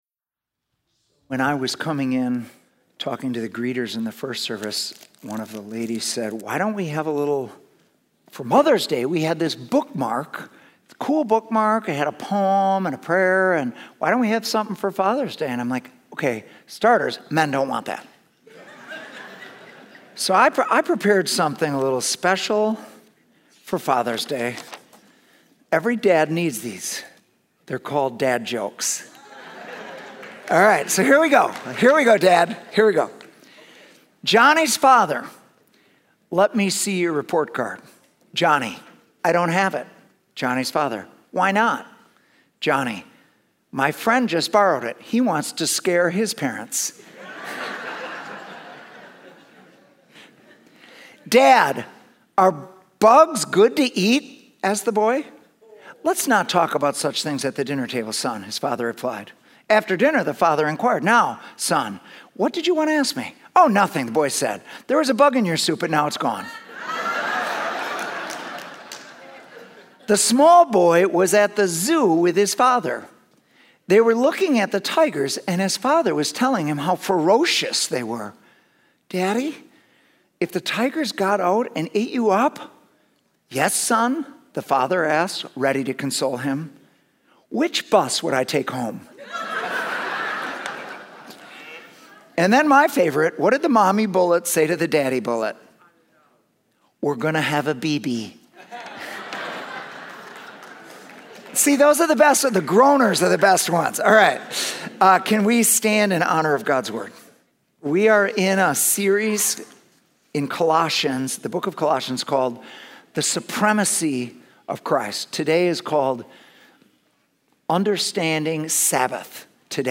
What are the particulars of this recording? Sunday Messages @ City Church